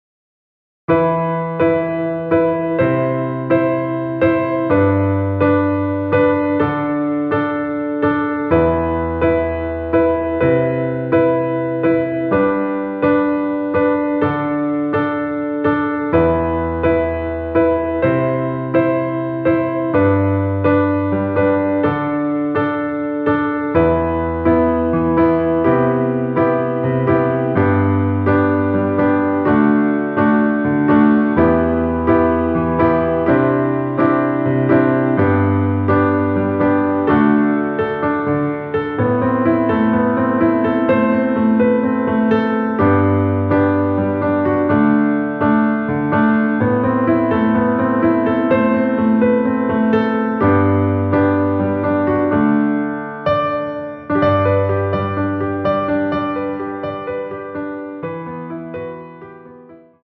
음정은 반음정씩 변하게 되며 노래방도 마찬가지로 반음정씩 변하게 됩니다.
앞부분30초, 뒷부분30초씩 편집해서 올려 드리고 있습니다.
중간에 음이 끈어지고 다시 나오는 이유는